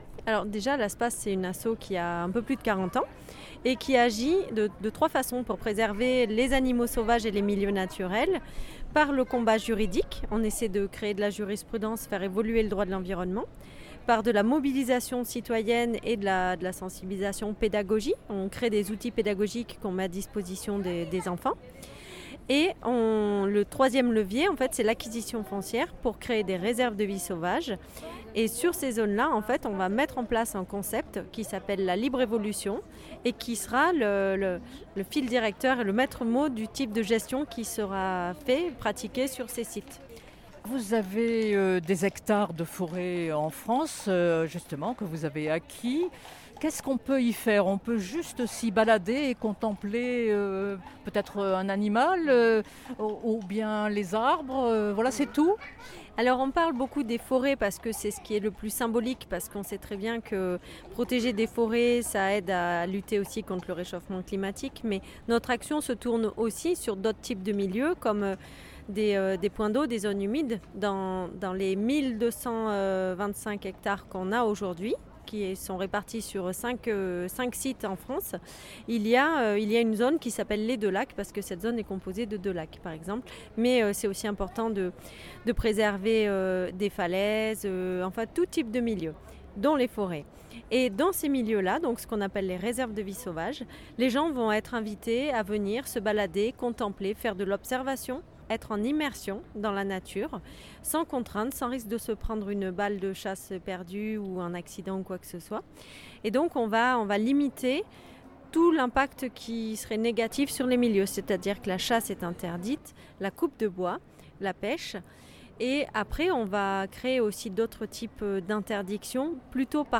Entretien.